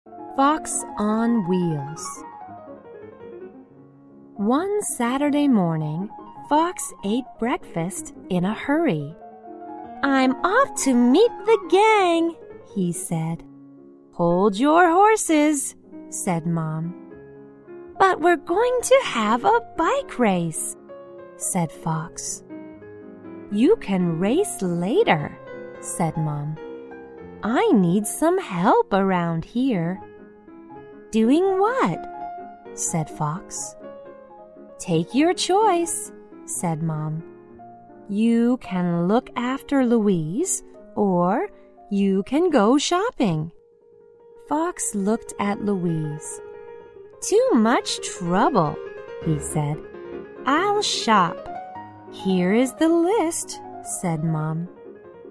在线英语听力室小狐外传 第49期:早餐的听力文件下载,《小狐外传》是双语有声读物下面的子栏目，非常适合英语学习爱好者进行细心品读。故事内容讲述了一个小男生在学校、家庭里的各种角色转换以及生活中的趣事。